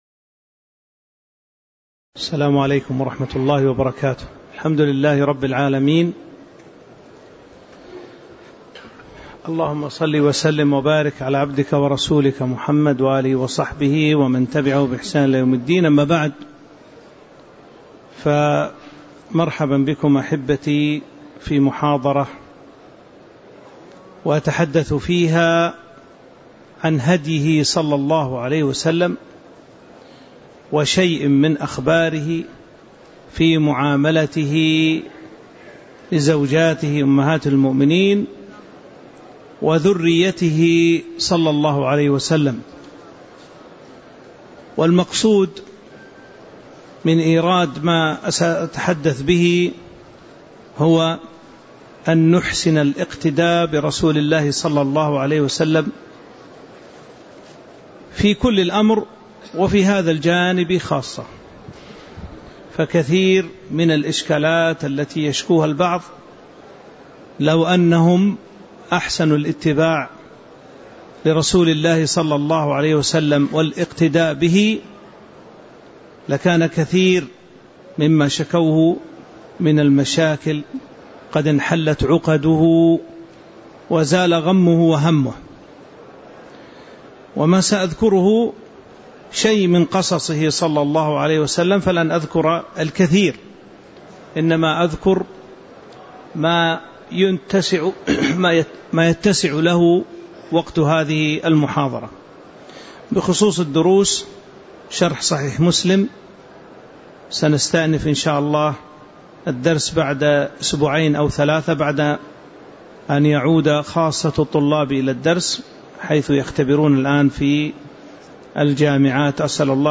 تاريخ النشر ١٥ جمادى الآخرة ١٤٤٦ هـ المكان: المسجد النبوي الشيخ